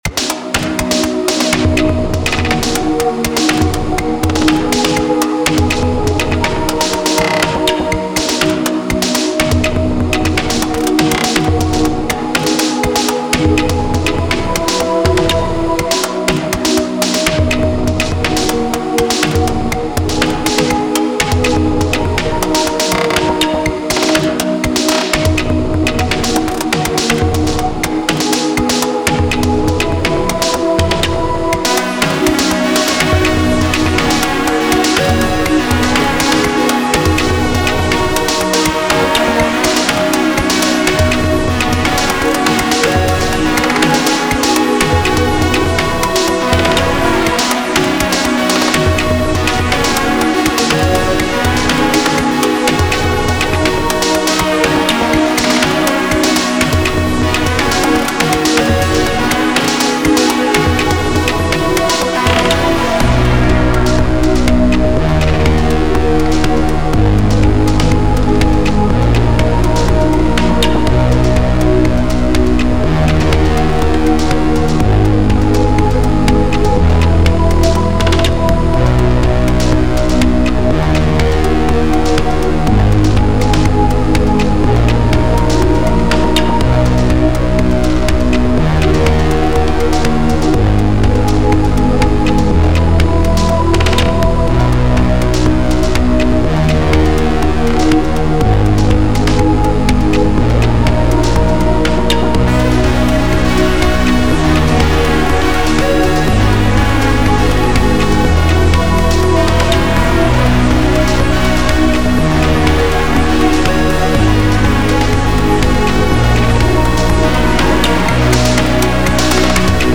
It’s all BA-1 apart from the drums that are Drum Computer by SugarBytes.